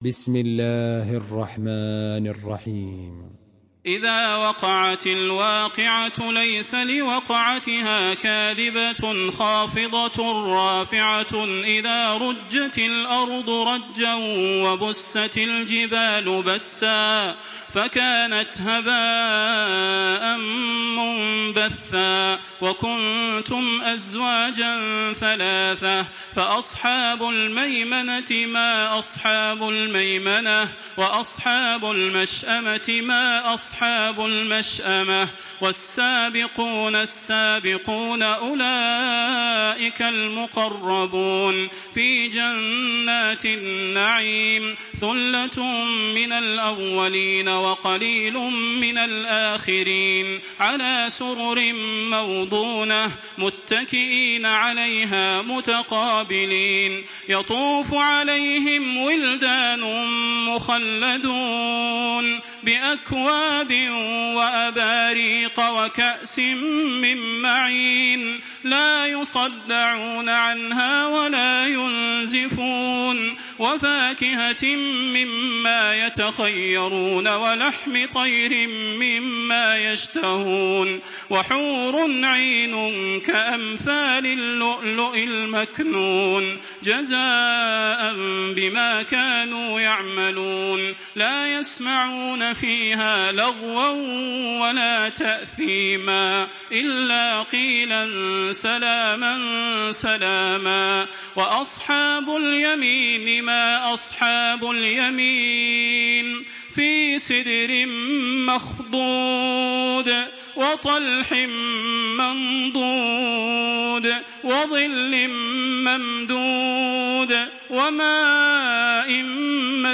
Makkah Taraweeh 1426
Murattal